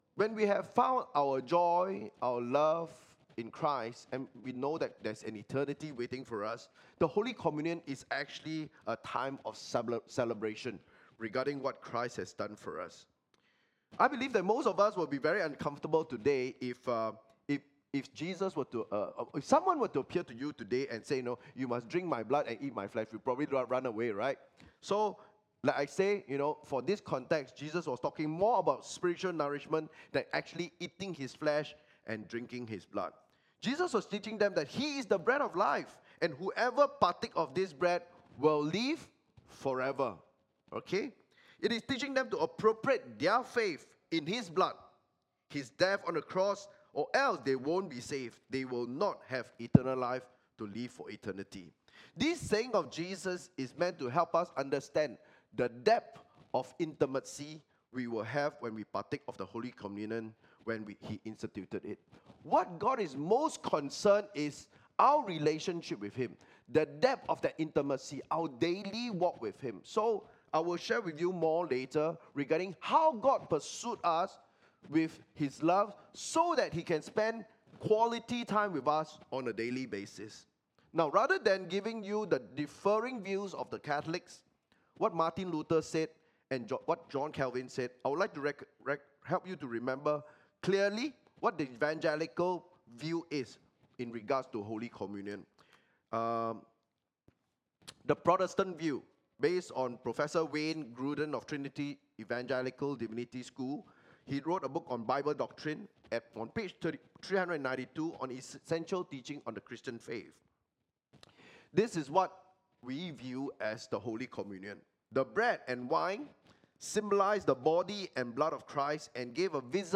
10am service Main Passage: John 6:52-58